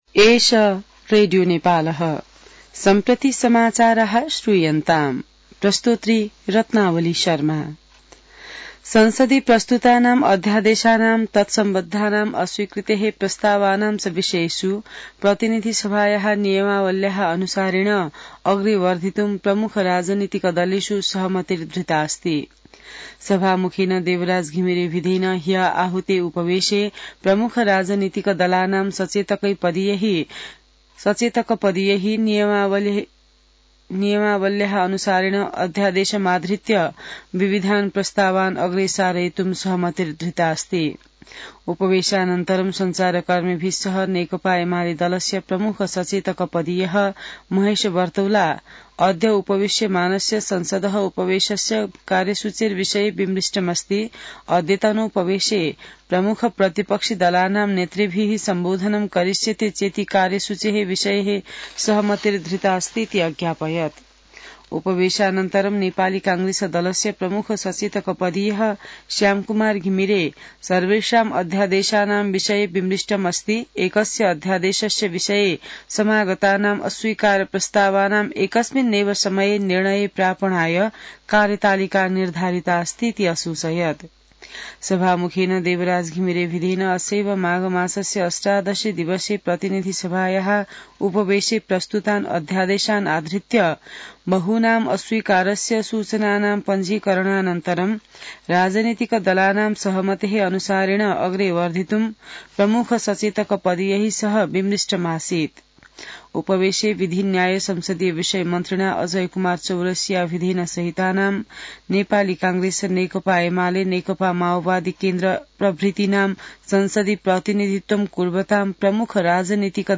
संस्कृत समाचार : २५ माघ , २०८१